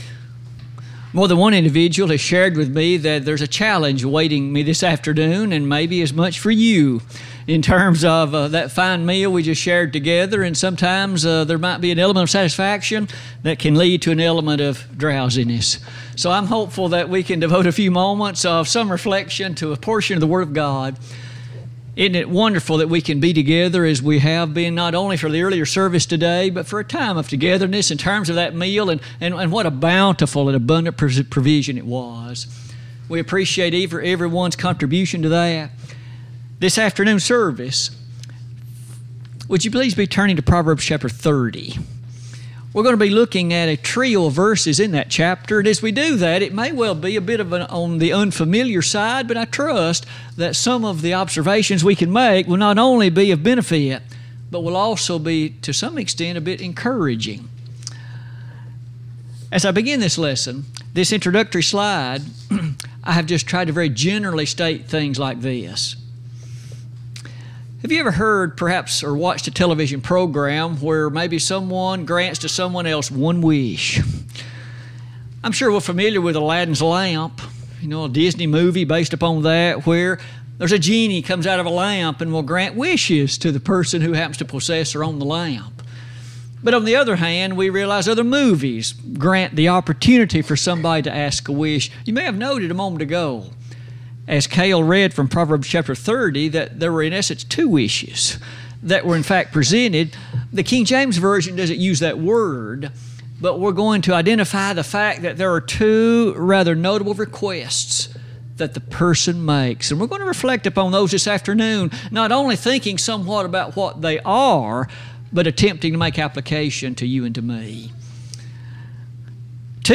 Sermons Recordings